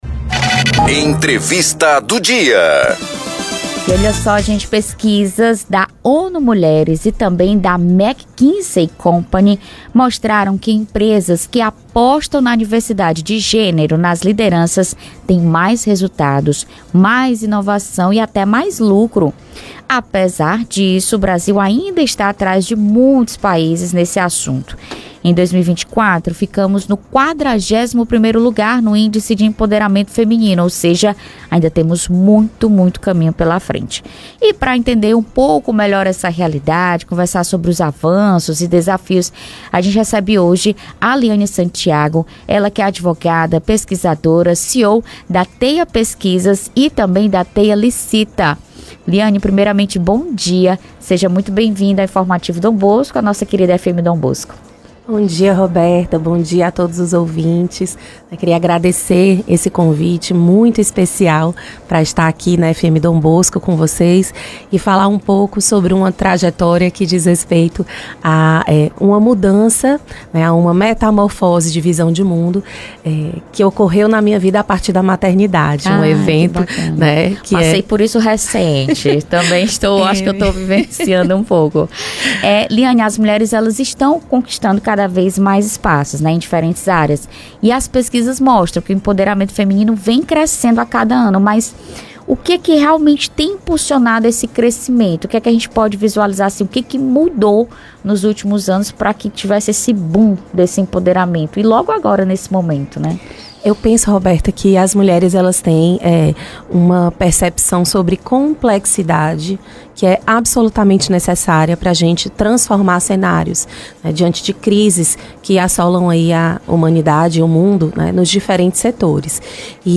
ENTREVISTA_MULHER-EMPREENDEDORA.mp3